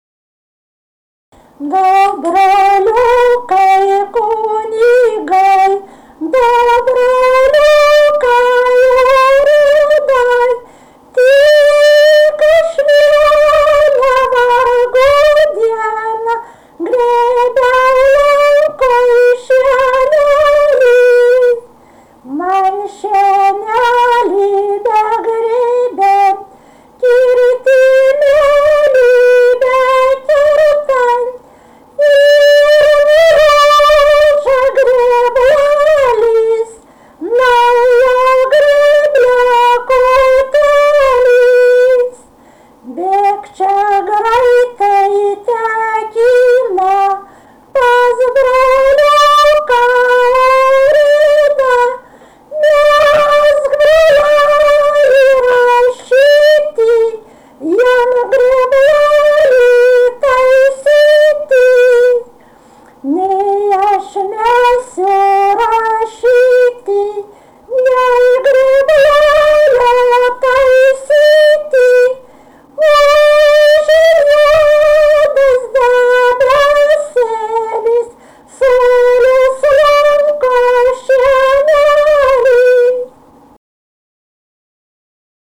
Dalykas, tema daina
Erdvinė aprėptis Dirvonai
Atlikimo pubūdis vokalinis